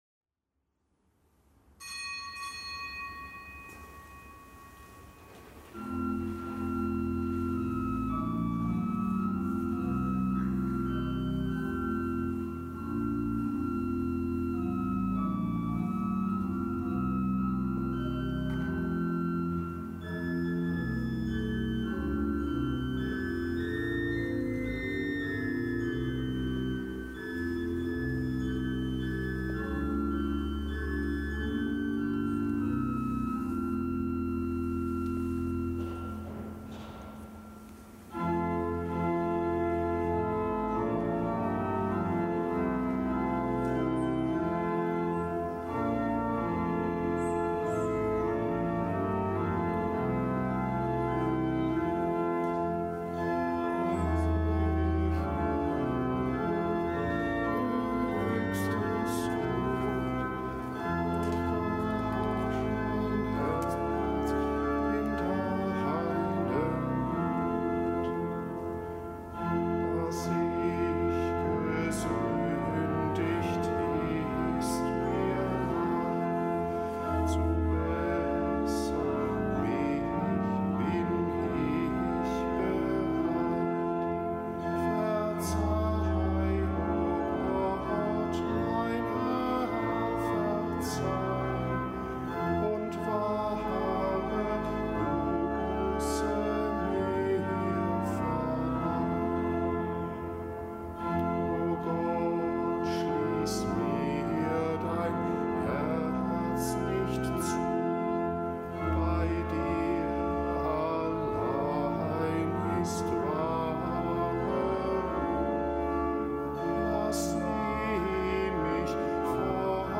Kapitelsmesse am Montag der dritten Fastenwoche
Kapitelsmesse aus dem Kölner Dom am Montag der dritten Fastenwoche